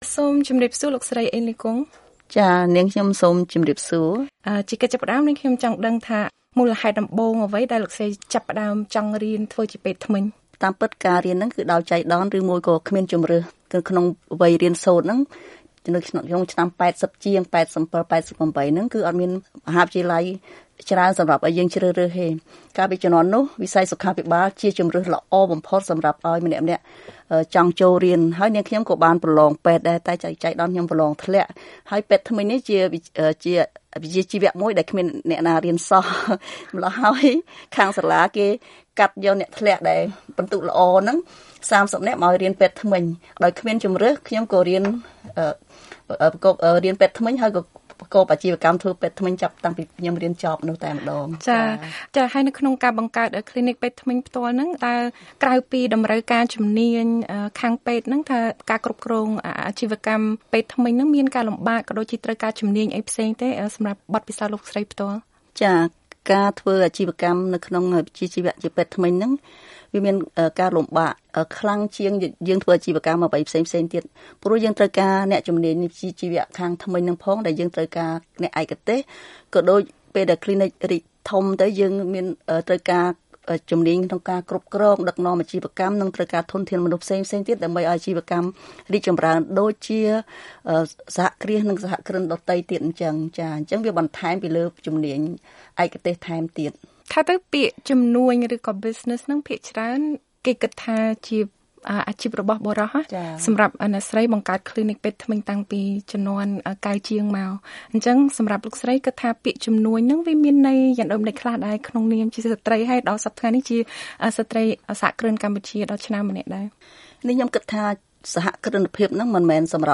បទសម្ភាសន៍ VOA